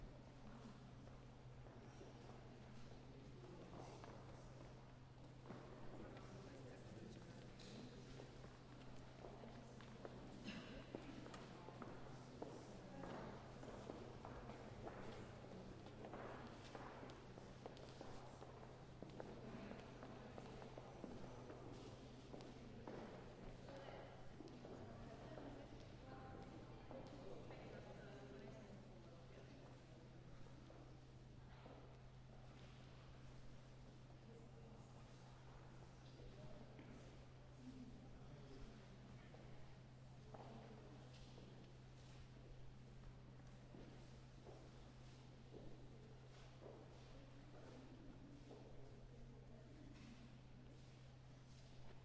描述：博物馆里的脚步声和谈话声，木地板
Tag: 氛围 现场记录